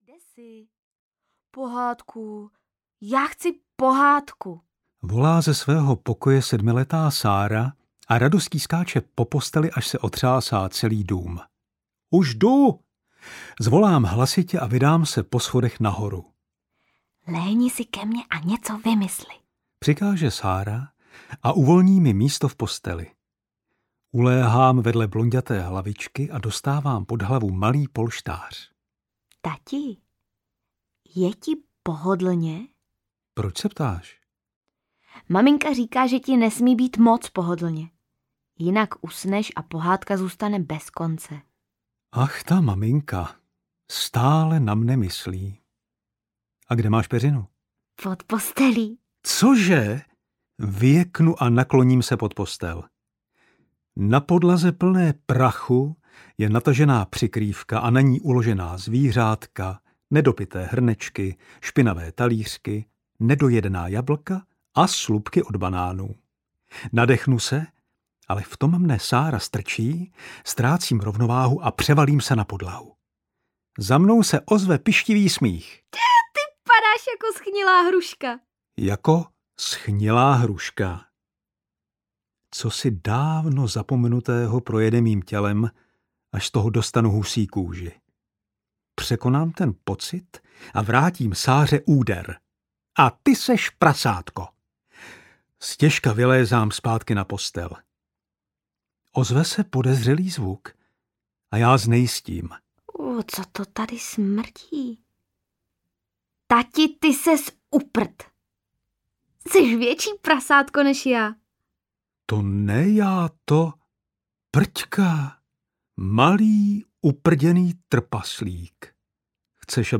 Časohrátky audiokniha
Ukázka z knihy